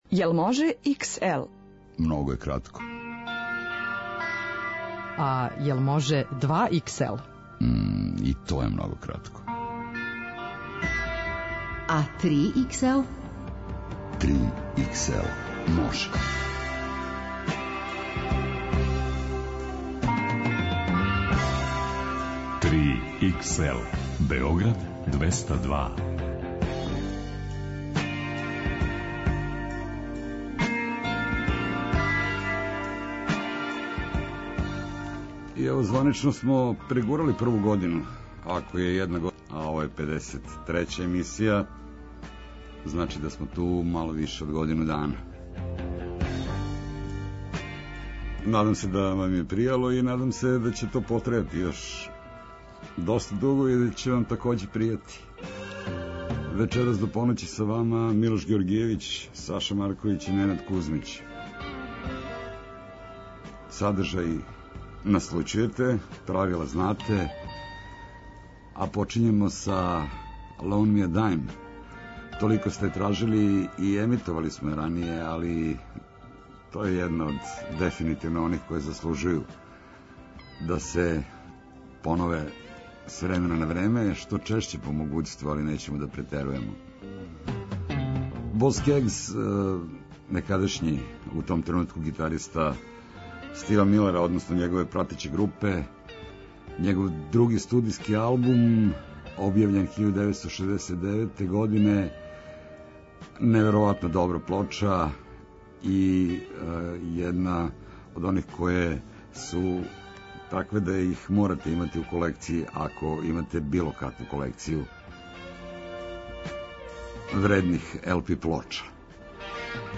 Најдуже музичке нумере.